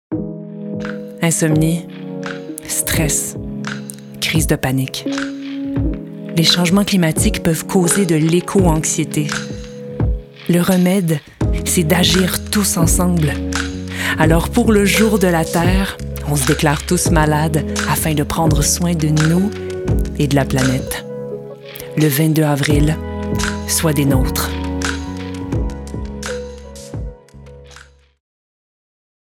Timbre Grave - Médium
Jour de la Terre - Empathique - Sérieuse - Québécois soutenu /
Pub sociétale + Annonceuse - Fictif 2023 0:29 1 Mo